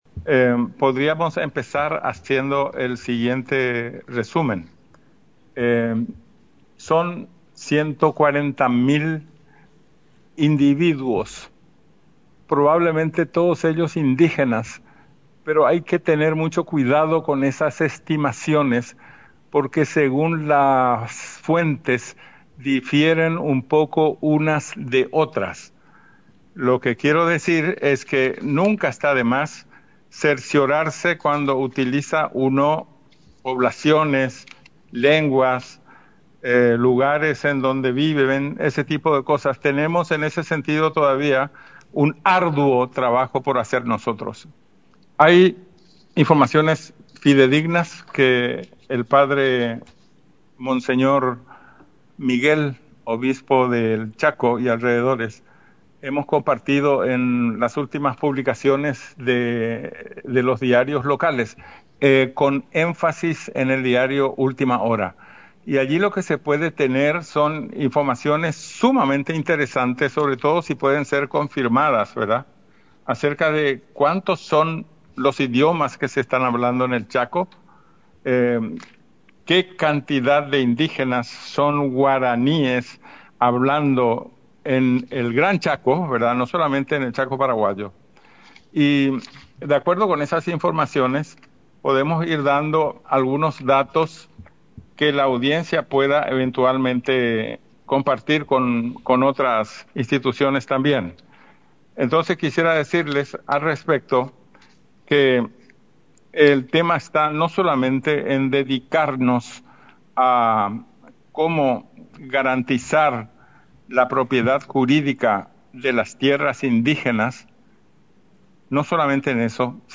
RESUMEN DE LA ENTREVISTA RADIAL DEL SÁBADO 18 DE ABRIL DE 2026 CON REPRESENTANTES DE ORGANIZACIONES DEL CHACO Y ADYACENCIAS (DESDE EL CHACO ARGENTINO HACIA EL NORTE HACIENDO PARTICIPE A TODO EL CHACO PARAGUAYO Y BOLIVIANO, SUPERÁNDOLOS CON LAS NUEVAS MEGA-CONSTRUCCIONES DE LA CARRETERA BIOOCEÁNICA E INSTALACIONES AD HOC.